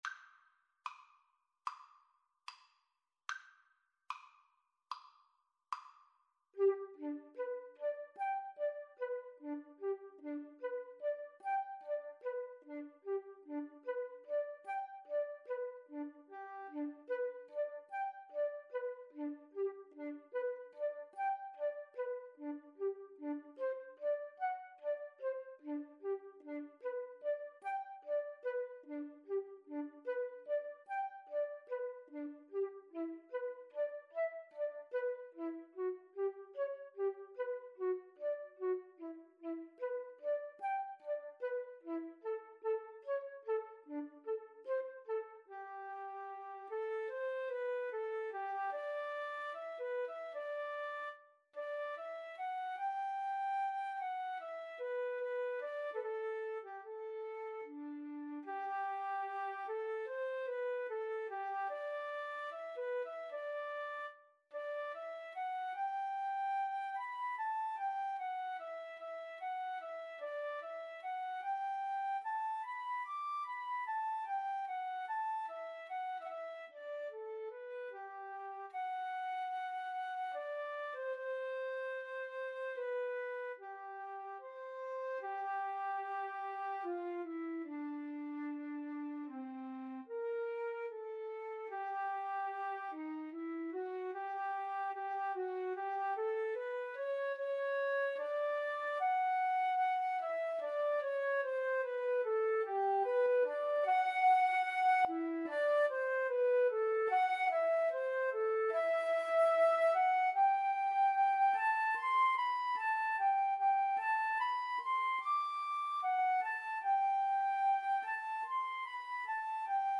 G major (Sounding Pitch) (View more G major Music for Flute Duet )
Andantino quasi allegretto ( = 74) (View more music marked Andantino)
Flute Duet  (View more Intermediate Flute Duet Music)
Classical (View more Classical Flute Duet Music)